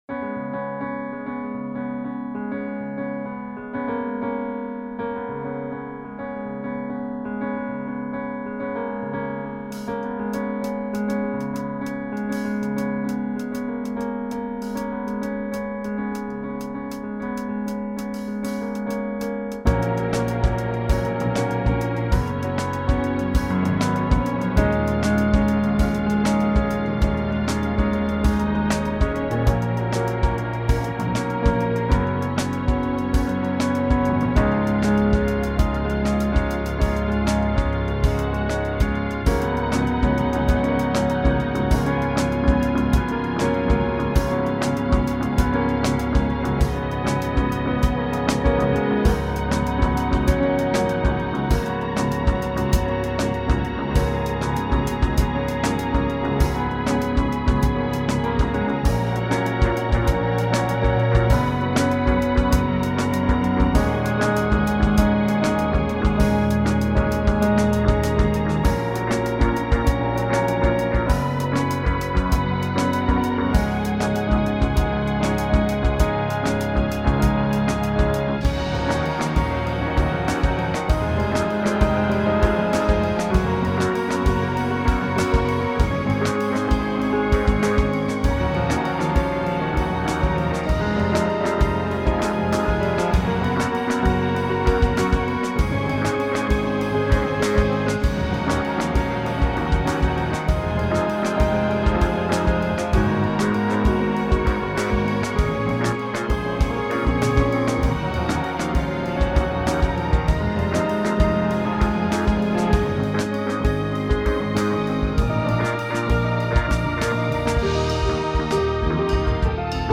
Friend of mine had two boyfriends going at the same time and couldn't choose which one to stay with (in the end, it was neither). Weird long solo sections. Stupid ending.